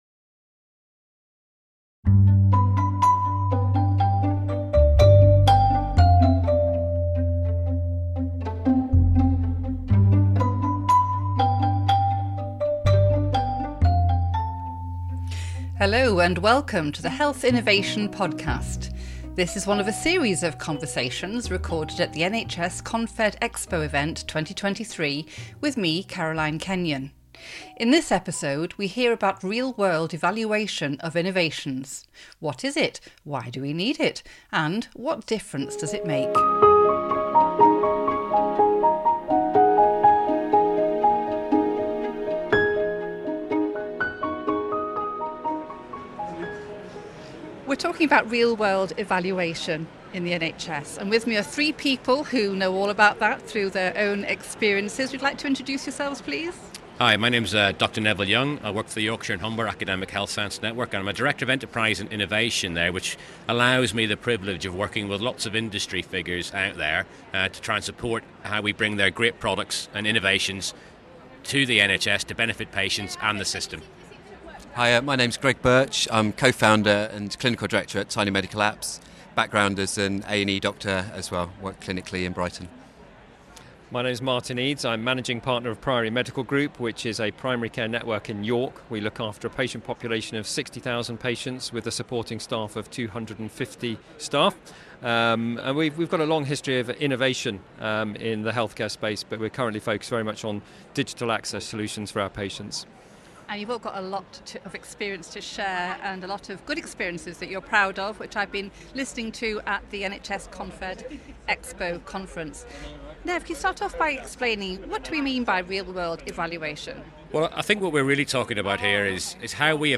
The episode was recorded at NHS ConfedExpo 2023 following a presentation in the Innovator Zone Theatre.